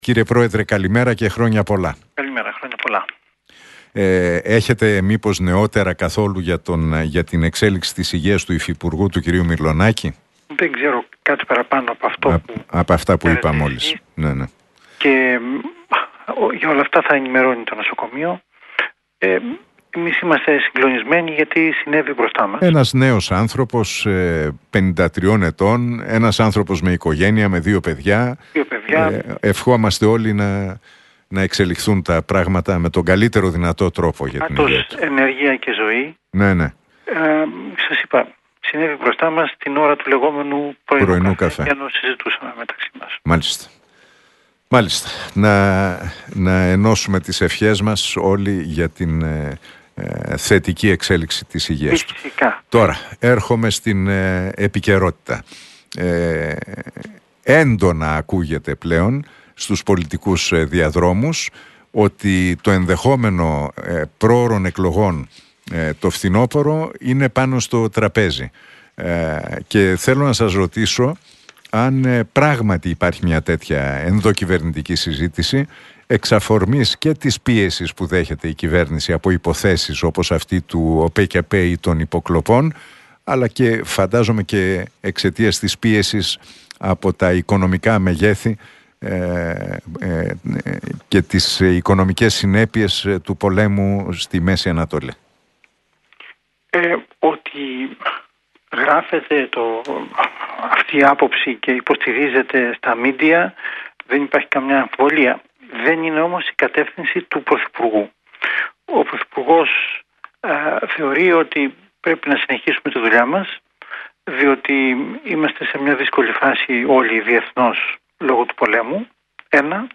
Για το ενδεχόμενο πρόωρων εκλογών, τη Συνταγματική Αναθεώρηση και την υπόθεση Λαζαρίδη μίλησε μεταξύ άλλων ο αντιπρόεδρος της κυβέρνησης, Κωστής Χατζηδάκης στον Realfm 97,8 και την εκπομπή του Νίκου Χατζηνικολάου.